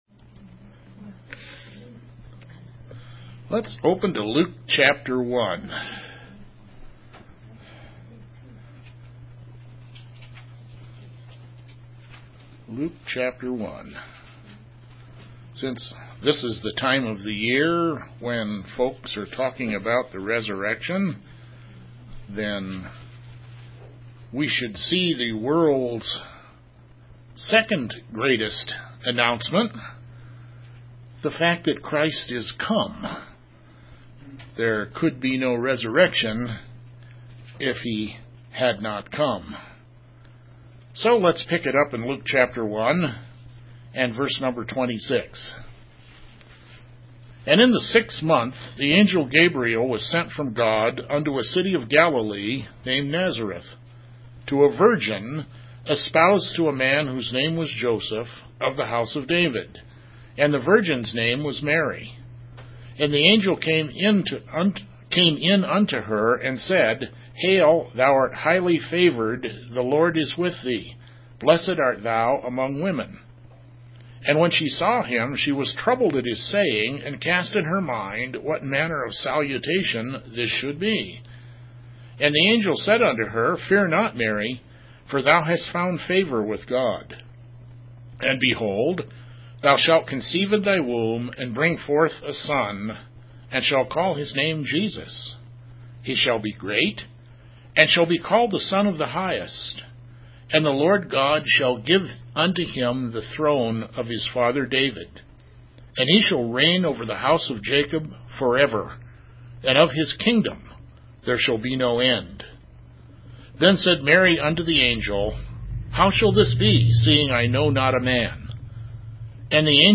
2018-03-25 AM Service
Sermons